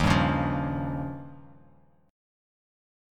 Listen to Eb9 strummed